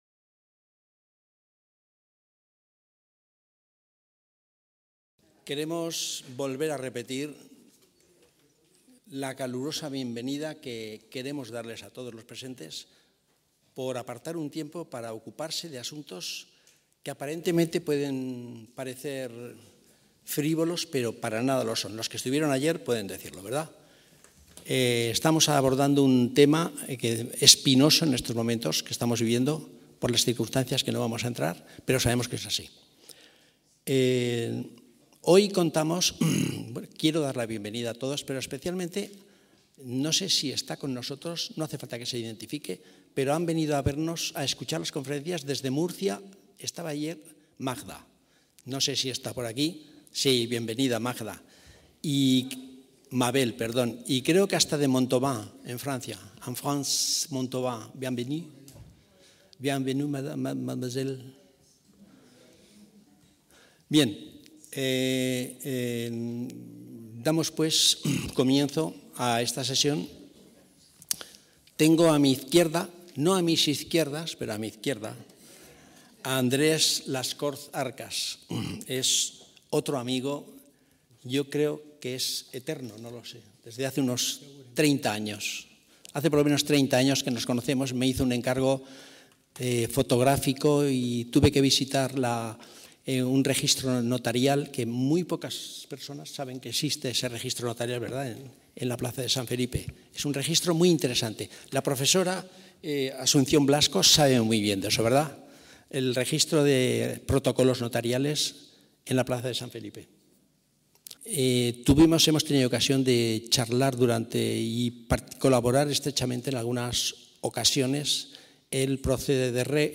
ACTOS EN DIRECTO - Los pasados 23 y 24 de octubre de 2024 tuvieron lugar en el Palacio de Alfajería de Zaragoza, por iniciativa y organización de Amistad Judeo-Aragonesa y Sefarad-Aragón sendos encuentros con ponencias, cuyo tema central es el auge del antisemitismo y la judeofobia durante este año.